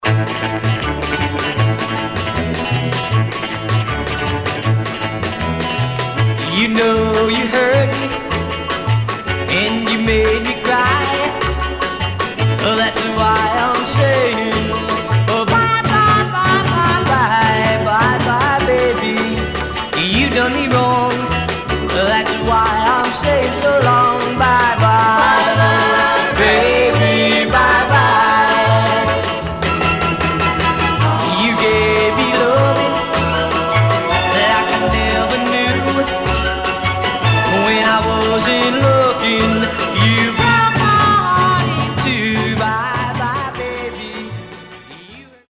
Stereo/mono Mono